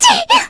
Cleo-Vox_Damage_kr_01.wav